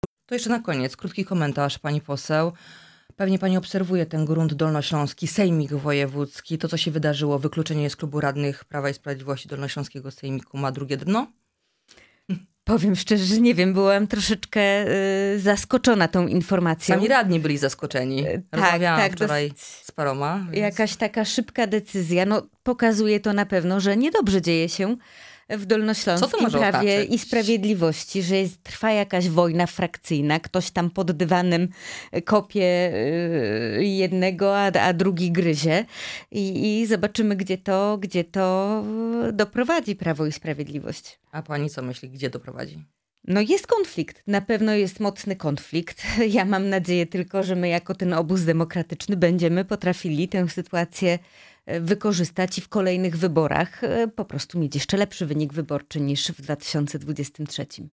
Posłanka na Sejm Małgorzata Tracz byłą naszym „Porannym Gościem”.